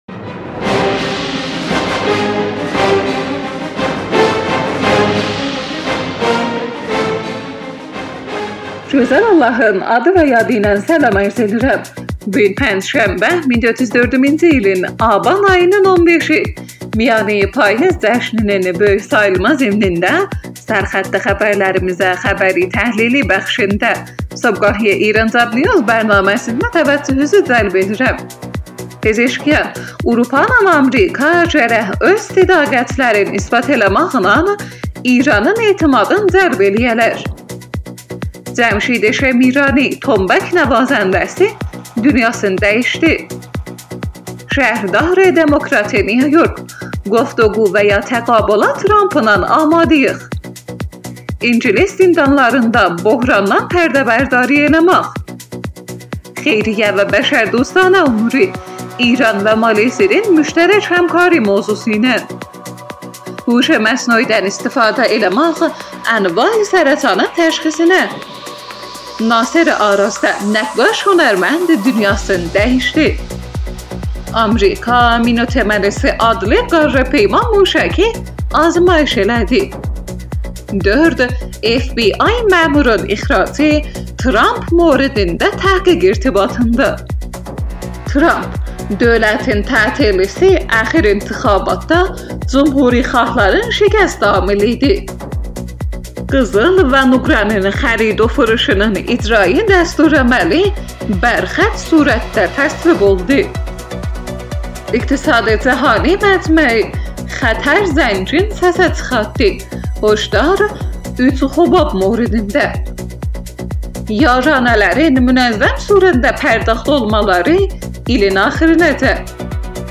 Iranjobnews Səhər xəbərləri.